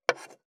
544魚切る,肉切りナイフ,まな板の上,包丁,ナイフ,調理音,料理,
効果音厨房/台所/レストラン/kitchen食器食材